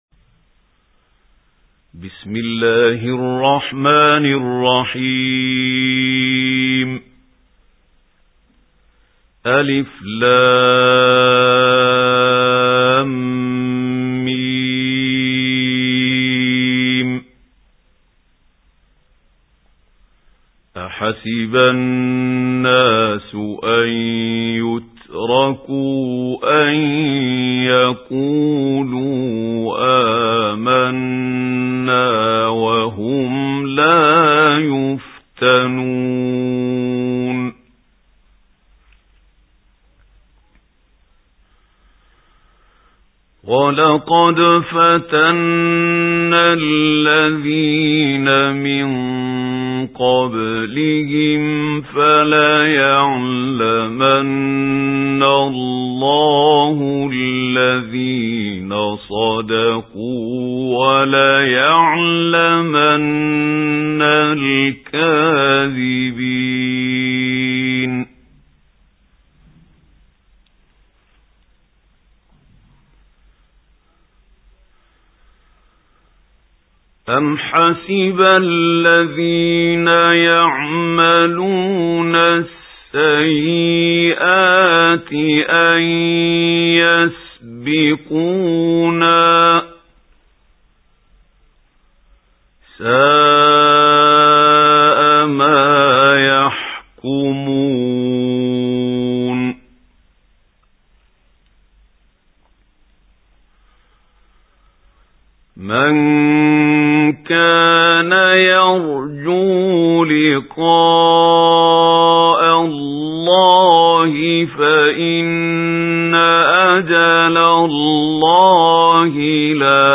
سُورَةُ العَنكَبُوتِ بصوت الشيخ محمود خليل الحصري